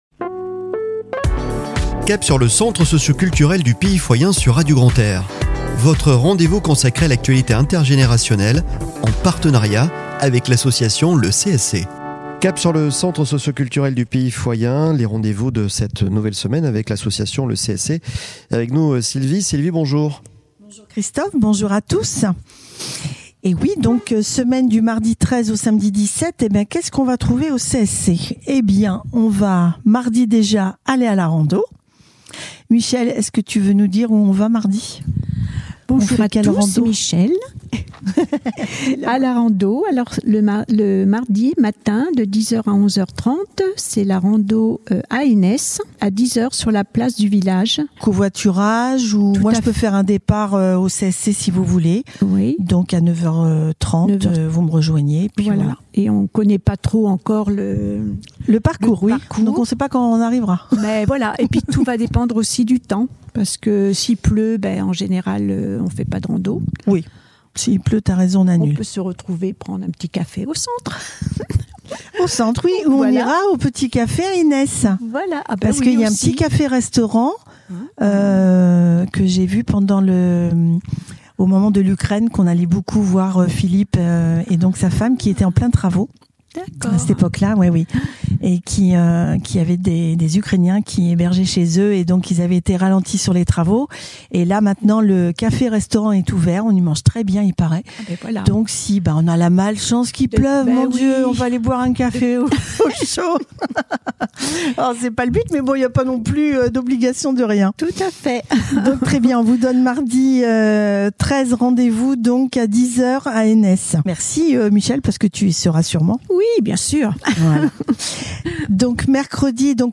Chronique de la semaine du 13 au 17 Février 2024 !